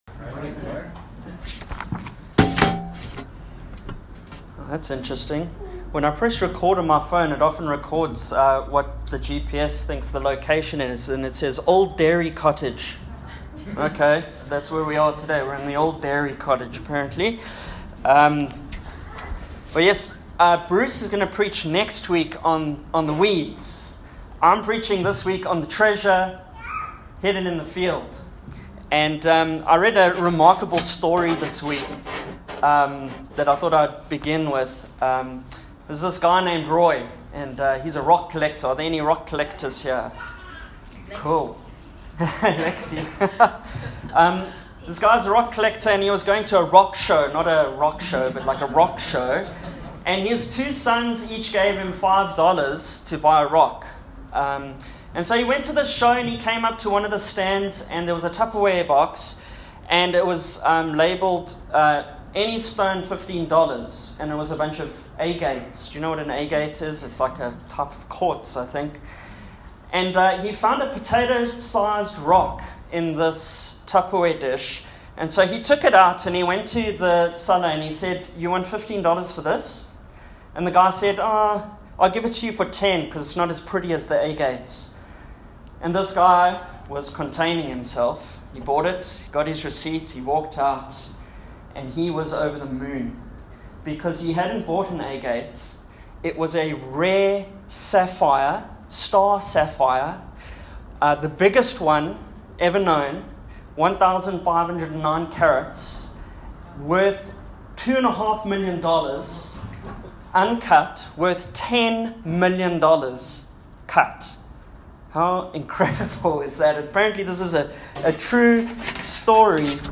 Passage: Matthew 13:44 Service Type: Sunday AM